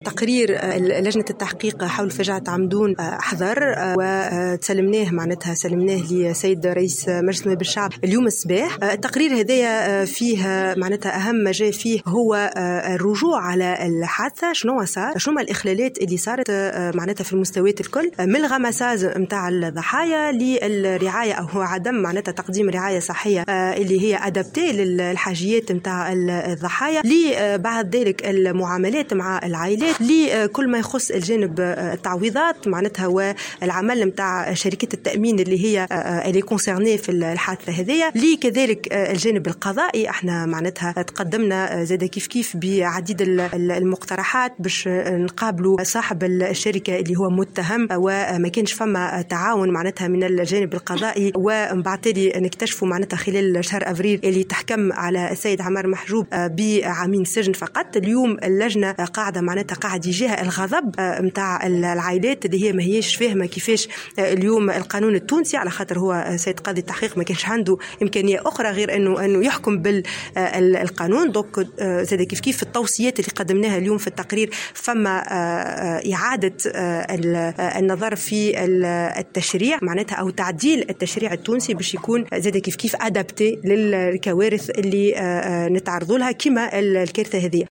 وأوضحت رئيسة لجنة التحقيق سيدة الونيسي في تصريح لمراسل الجوهرة "اف ام"، خلال ندوة صحفية عقدتها اليوم في البرلمان، أن اللجنة خلُصت إلى ملاحظات في القضية أهمها عدم تقديم الرعاية الصحية الضرورية للضحايا وعدم تمكين عائلاتهم من التعويضات المستحقة، منتقدة عمل شركات التأمين المعنية بالحادثة.